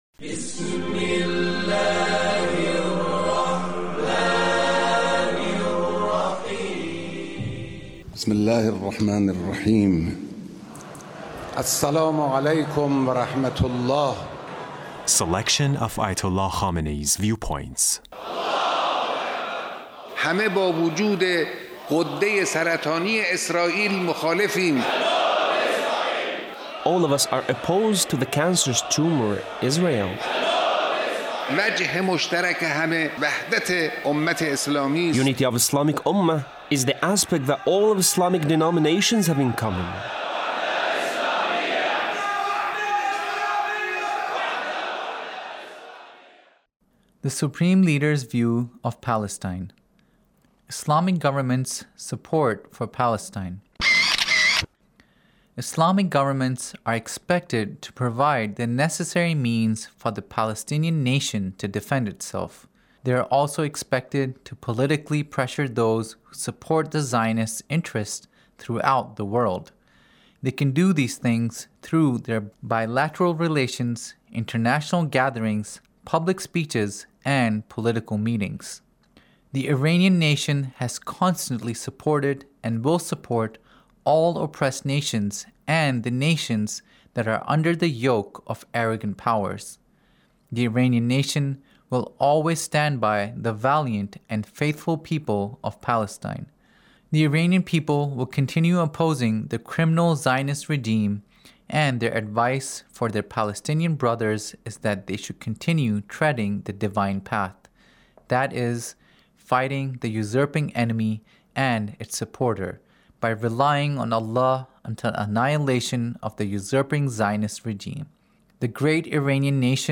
Leader's Speech on Palestine